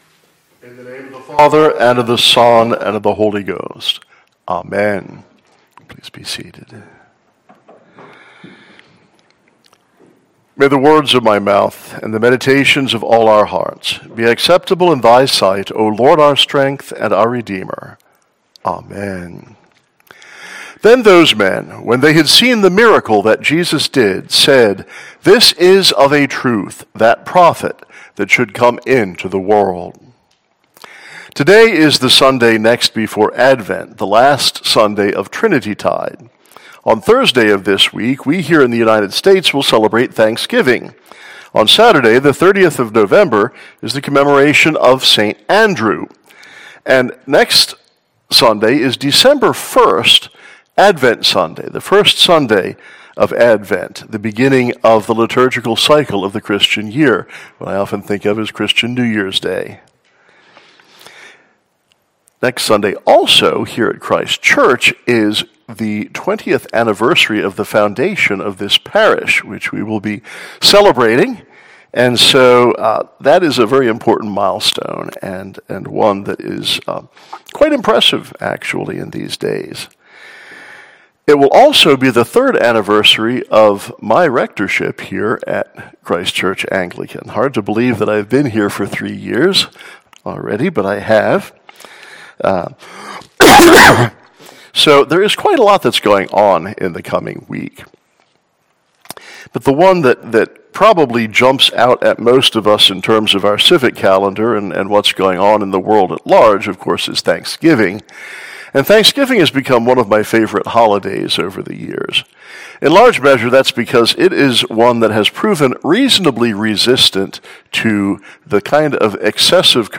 Listen to the sermon for the Sunday next before Advent.